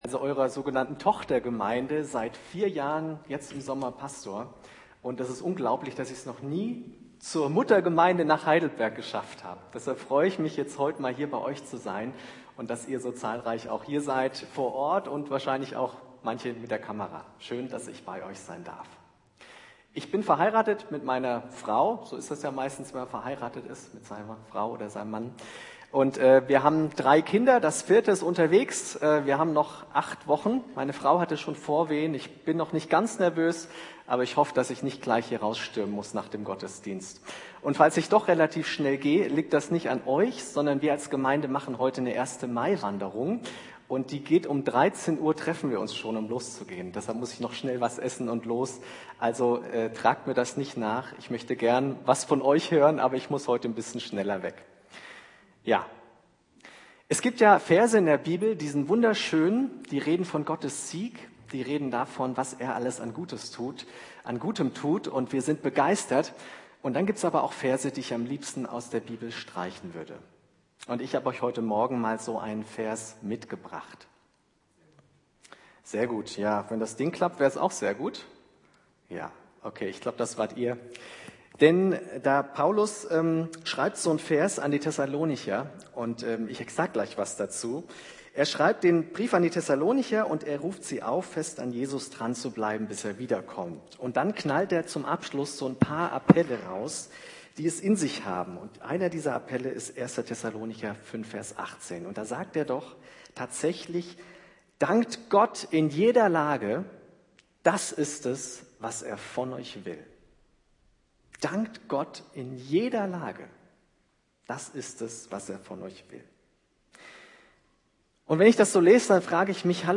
Mai 2022 Predigt 1.Thessalonicher , Briefe , Neues Testament Sie sehen gerade einen Platzhalterinhalt von YouTube .